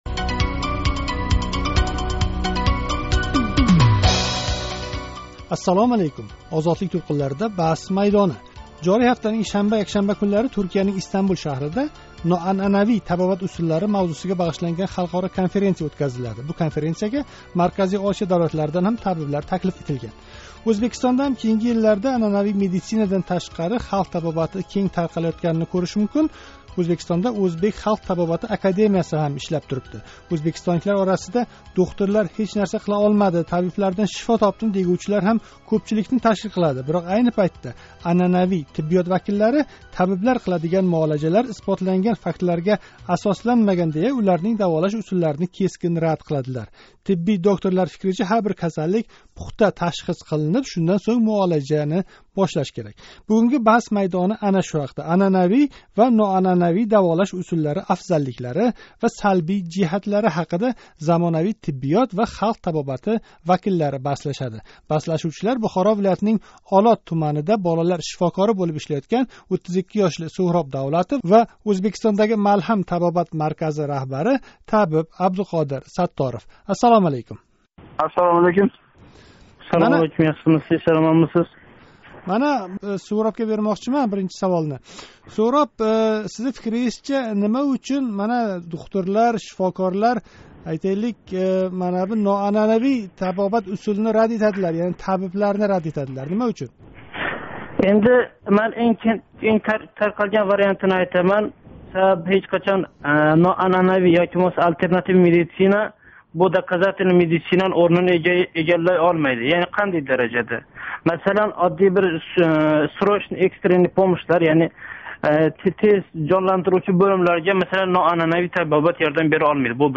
Бугунги баҳс майдонида анъанавий ва ноанъанавий даволаш усуллари афзалликлари ва салбий жиҳатлари ҳақида замонавий тиббиёт ва халқ табобати вакиллари баҳслашади.